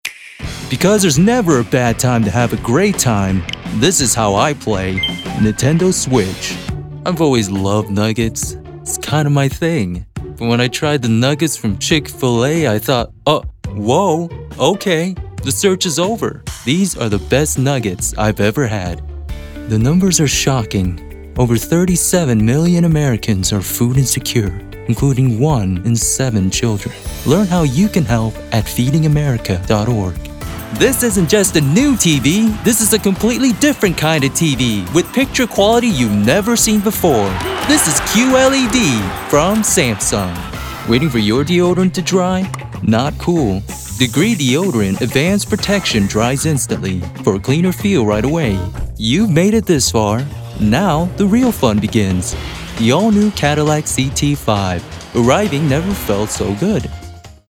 Voice Over Talent
Commercial Demo
Laidback with a richness that adds a little something extra.
His voice is clear and effective, ideal for the communication of ideas, products, and concepts.
Warm, engaging, and approachable with a professional turnaround and top-quality audio.